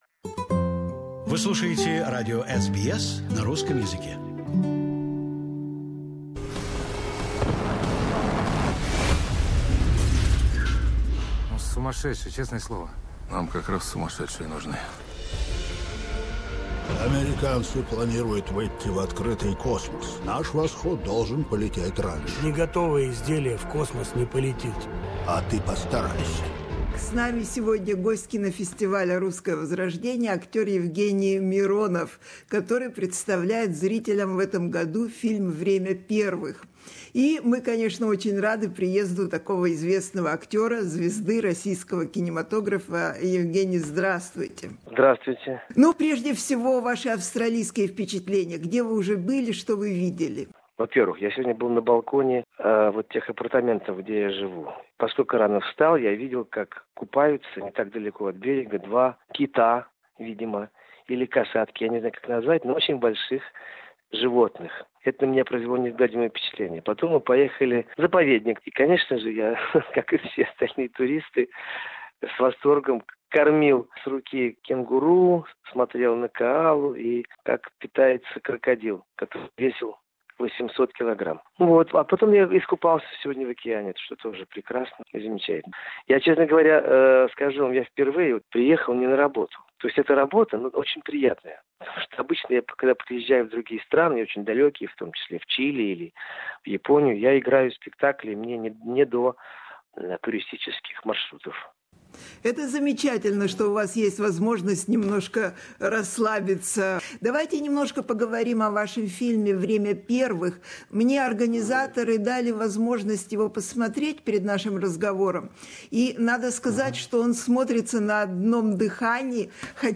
Speaking from Brisbane after presentation of his film Spacewalkers he shared his surprise and admiration from Australian experience. Yevgeny Mironov admits that the role of the first man stepping out to open Space was very demanding, especially because the hero cosmonaut Alexey Leonov himself with family was casting a critical eye over the film.